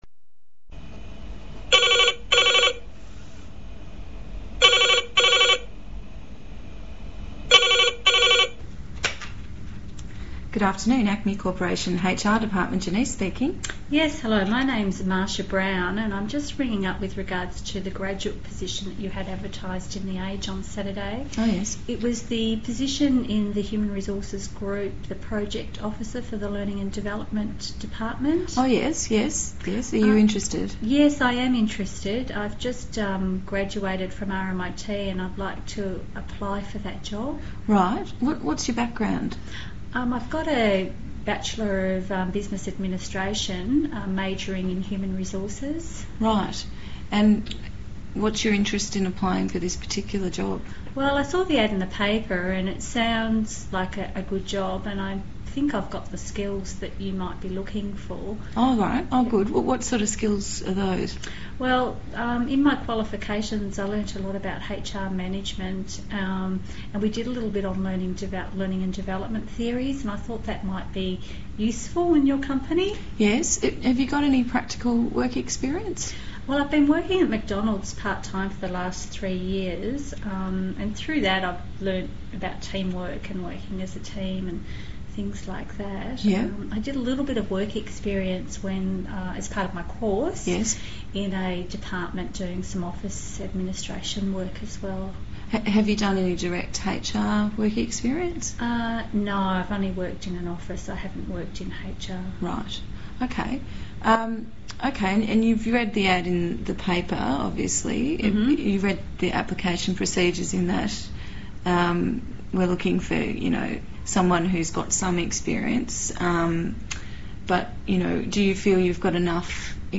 Listening to conversations